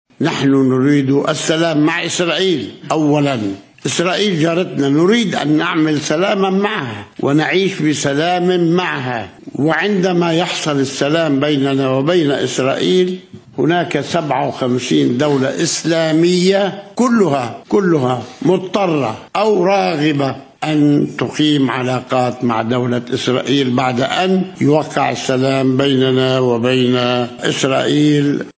انطلق منذ قليل مؤتمر صحفي مُشترك للرئيس الفرنسي إيمانويل ماكرون و الرئيس الفلسطيني محمود عبّاس في إطار جولة يقوم بها بعد قرار الجمعية العامة لمجلس الأمن الدولي حول القدس.